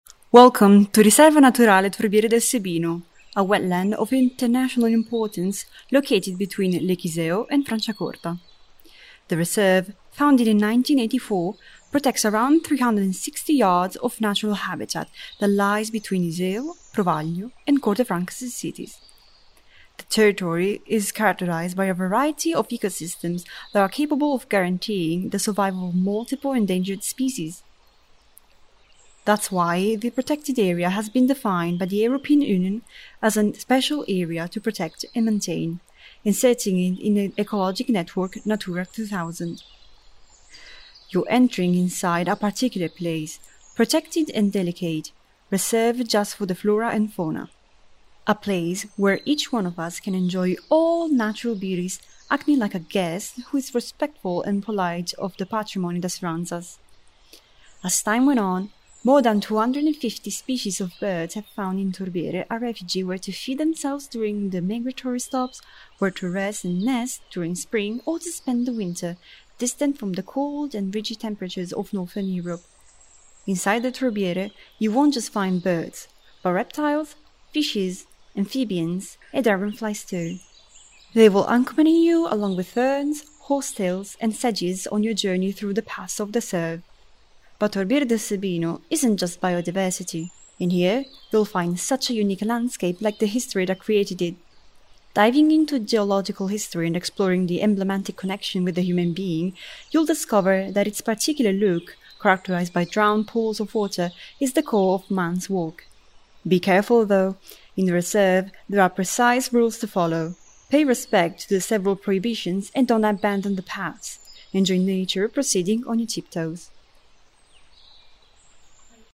Audioguide 1